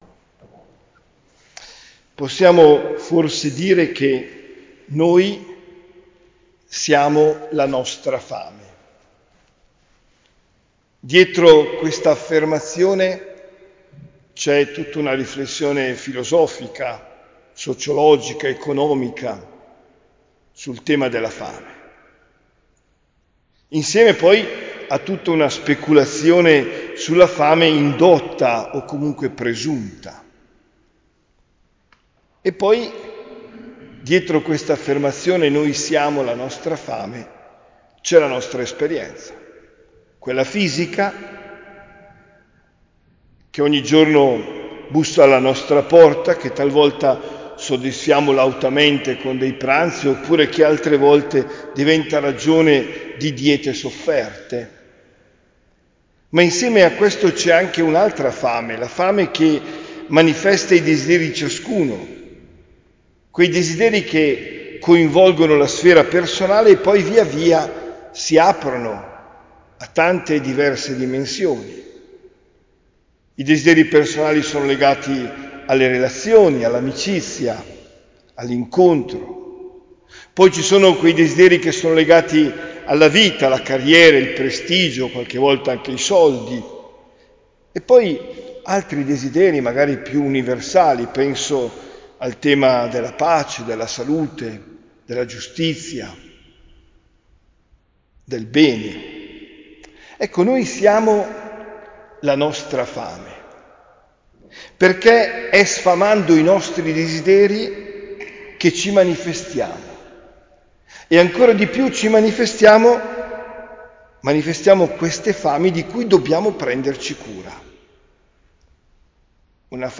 OMELIA DEL 18 AGOSTO 2024